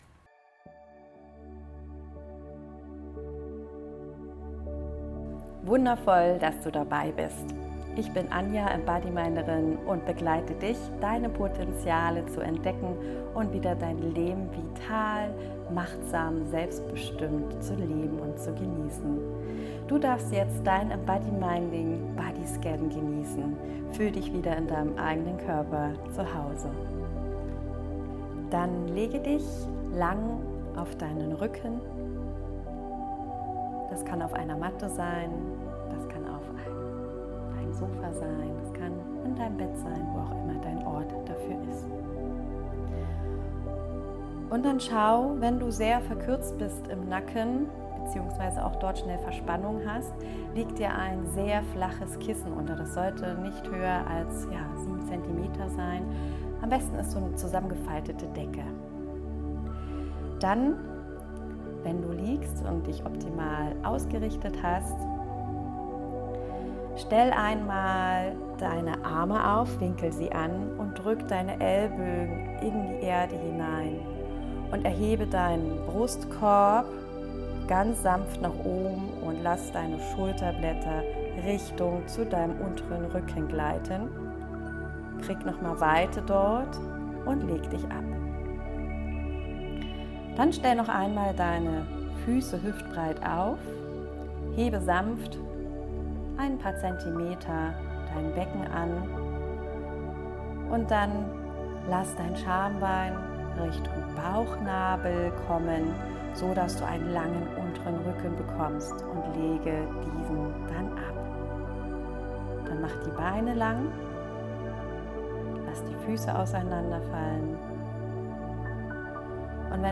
eBM_Dein-Bodyscan.mp3